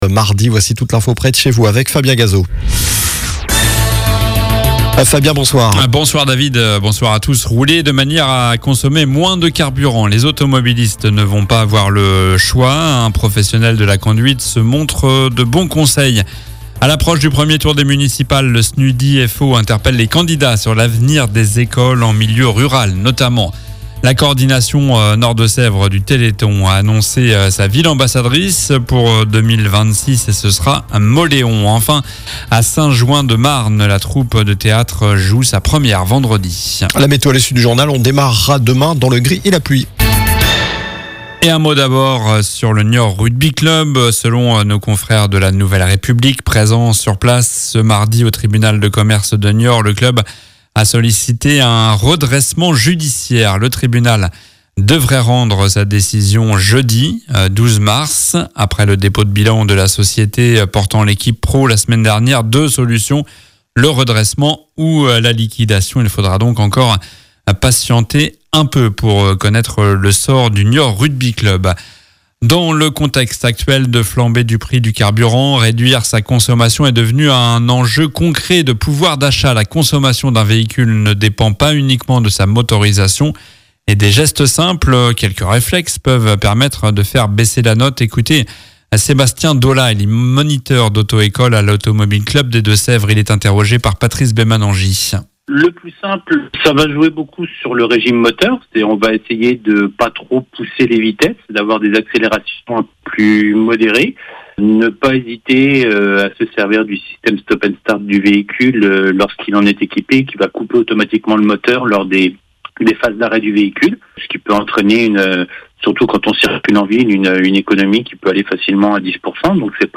Journal du mardi 10 mars (soir)